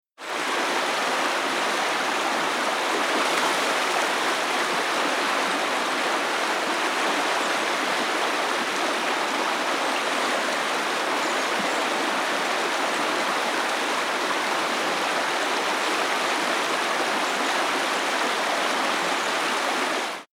For the salmon in this particular river in Southeast Alaska, the riffle captured in this recording is the final obstacle before reaching a large, calm mountain lake. Salmon can be heard splashing through the riffle, and woodland birds come and go. A calm, soothing track for relaxation, meditation, or sleep.
Salmon-Riffle-sample.mp3